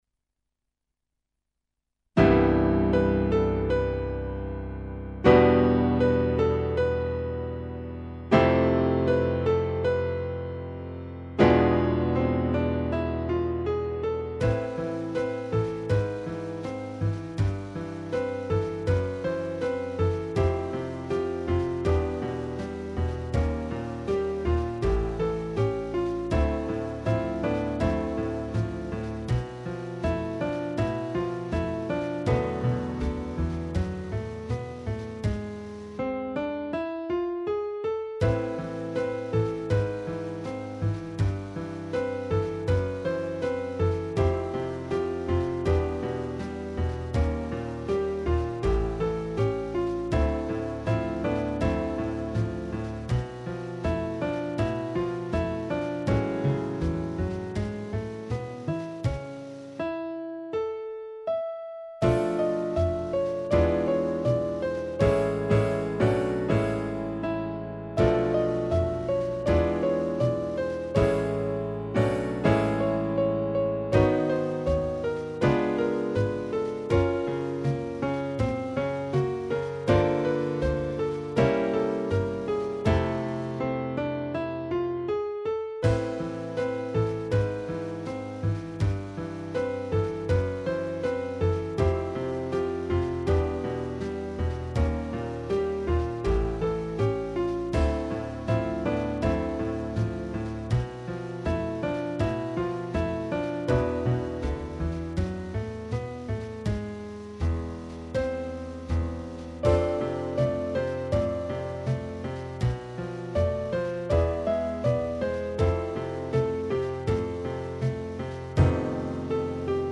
Piano Bass & Drums only